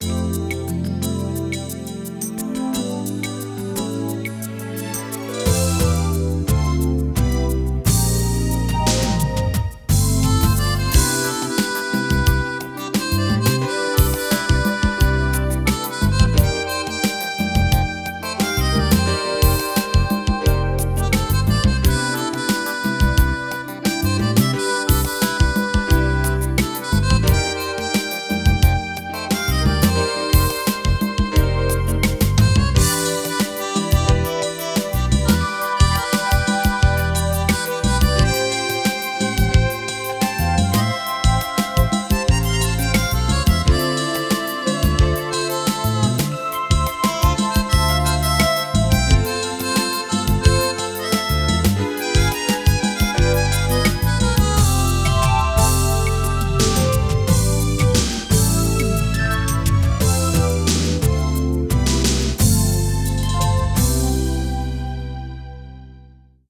• On-Board Demos